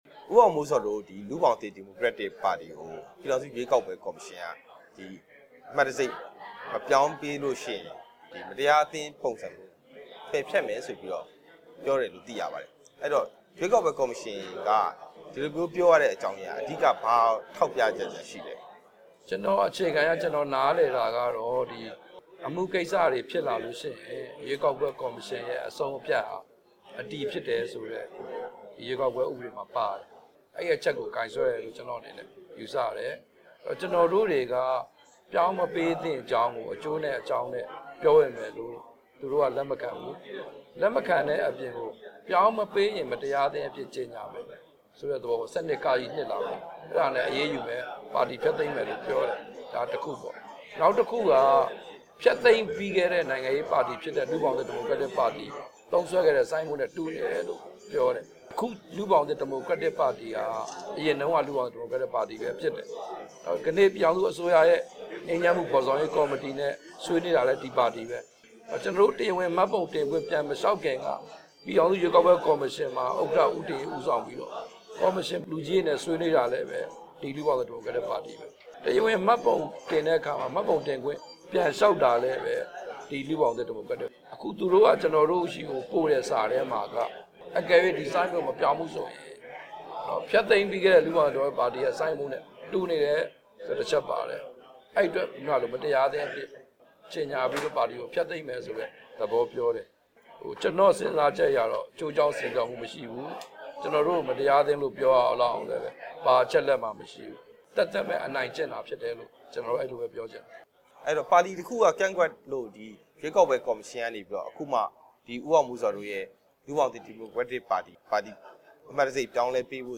လူ့ဘောင်သစ်ဒီမိုကရက်တစ်ပါတီရဲ့အလံပုံစံအကြောင်း မေးမြန်းချက်